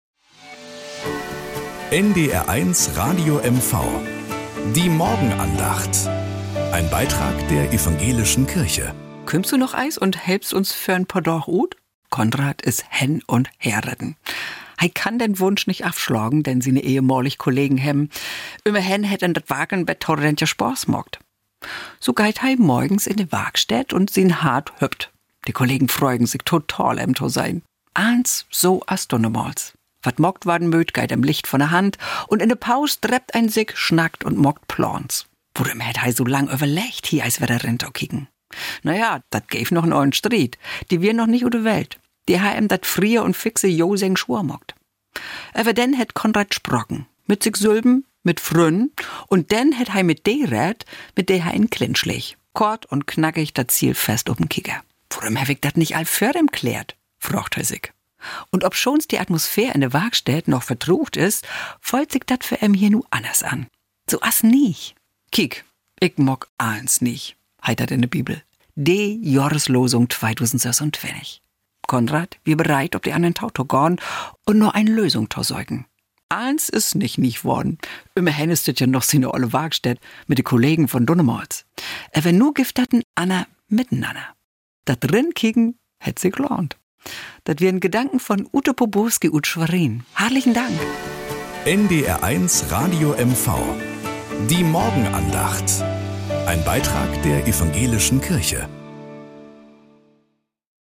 Morgenandacht.